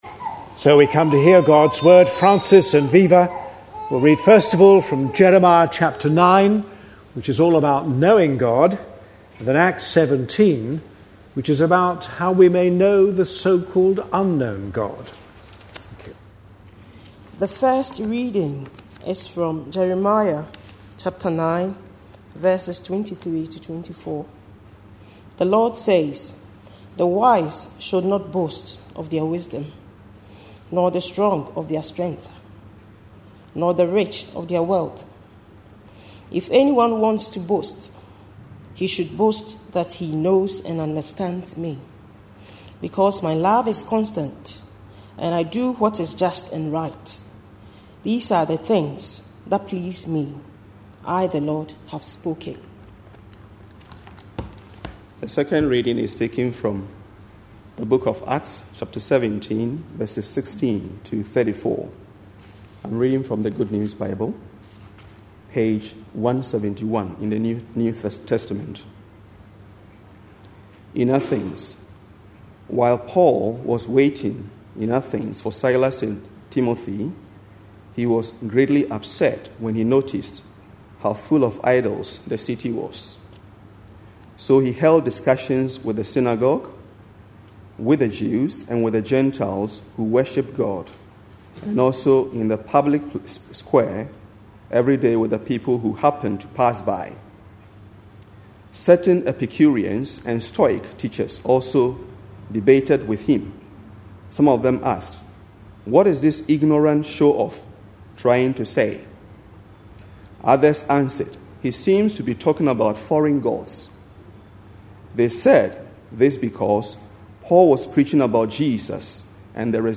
A sermon preached on 25th July, 2010, as part of our Acts series.